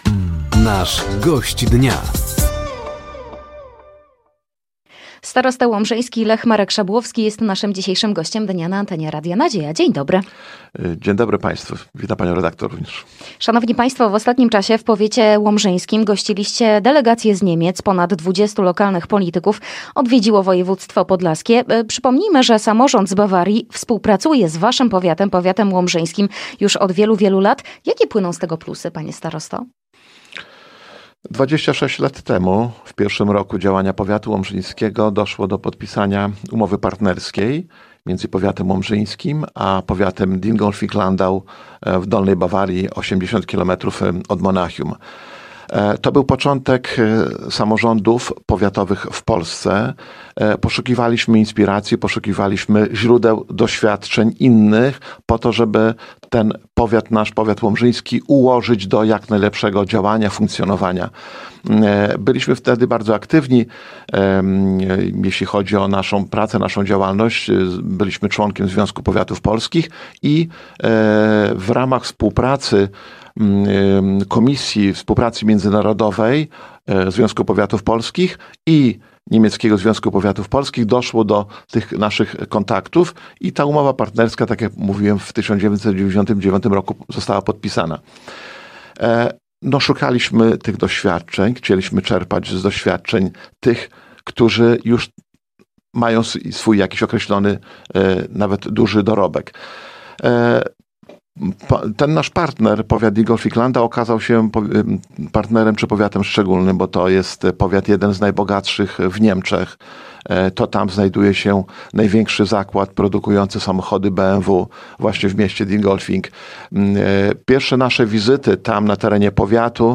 Gościem Dnia Radia Nadzieja był Lech Szabłowski, starosta łomżyński. Tematem rozmowy była delegacja samorządowców z Niemiec w województwie podlaskim oraz współpraca między powiatem łomżyńskim, a powiatem Dingolfing-Landau